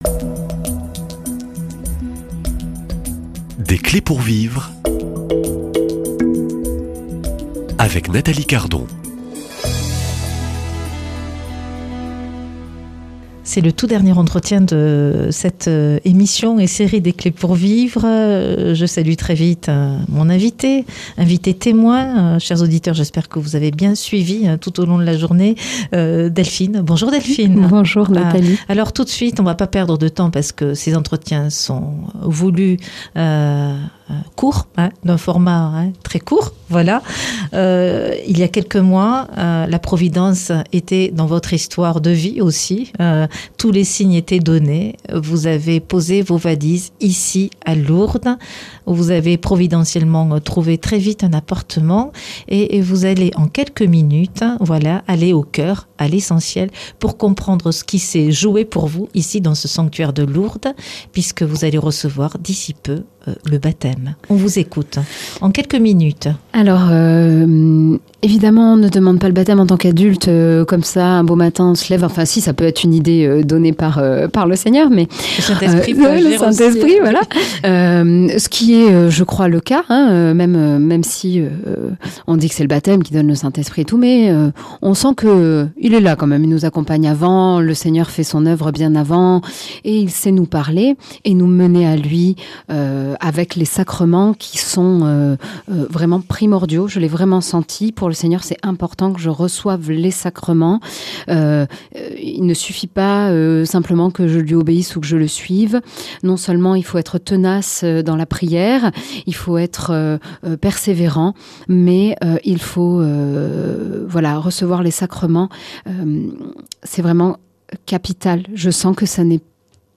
Témoignages \ Des clés pour vivre